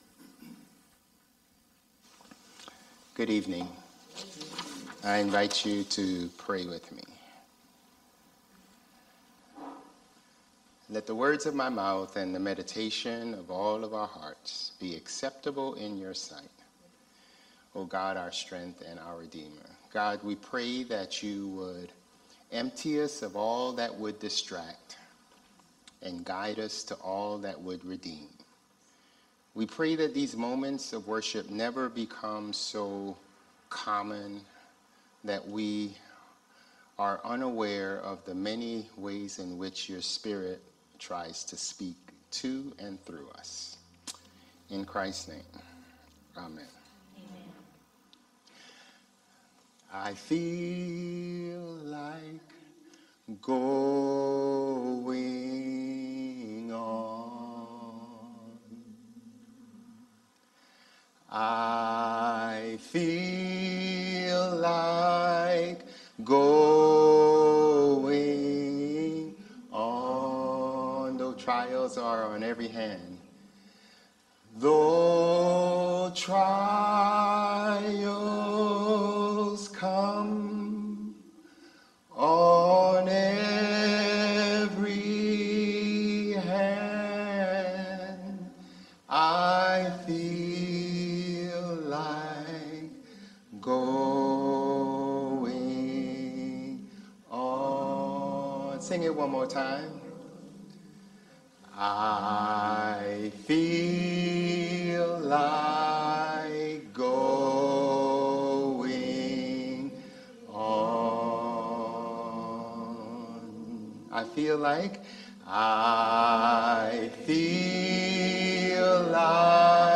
Sermons | Bethel Lutheran Church
November 10 Worship